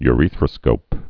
(y-rēthrə-skōp)